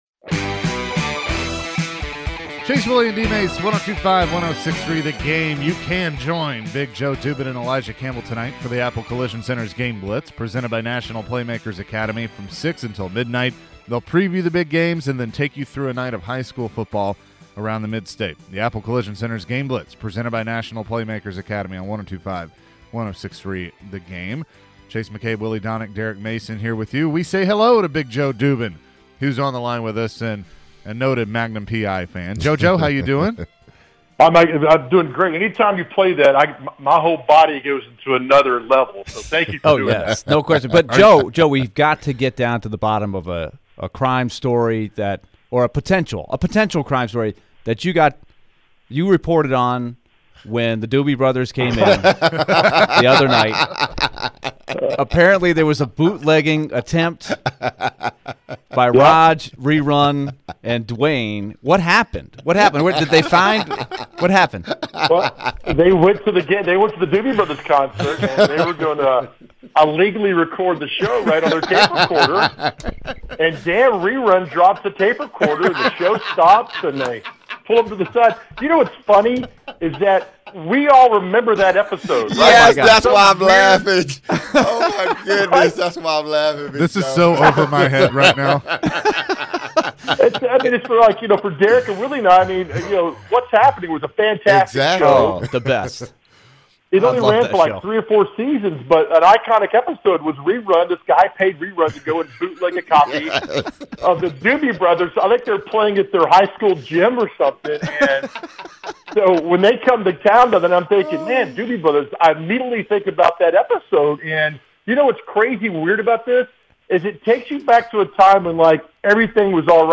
Game Blitz interview